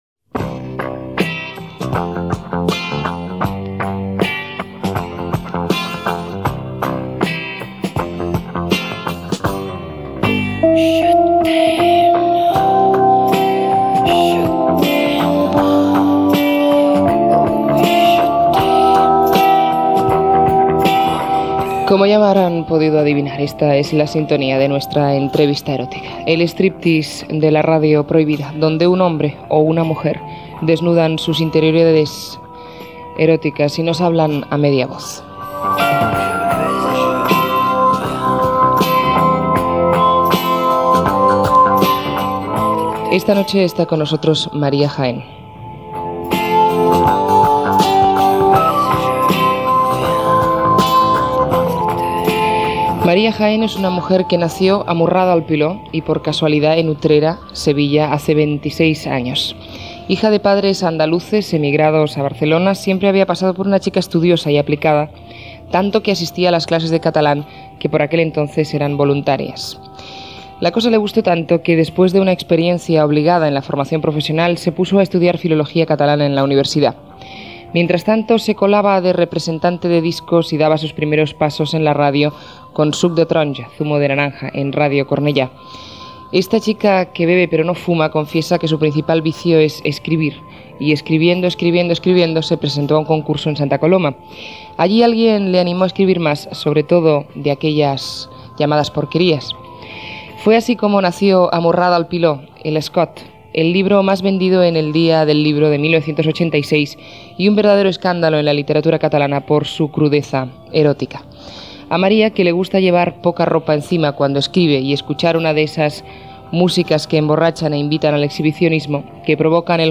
Por fin solos: entrevista